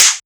Snare OS 06.wav